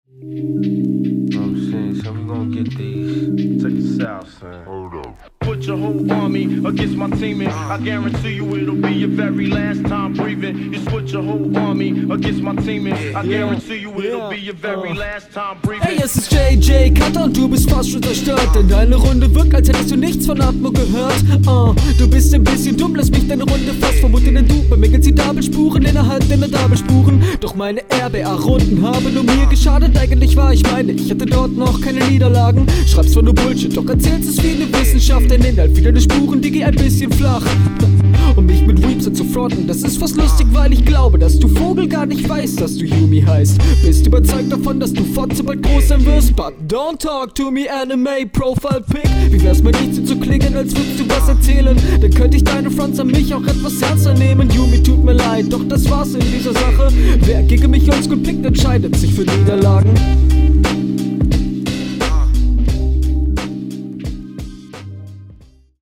Der Stimmeinsatz kommt auf dem Beat sehr cool.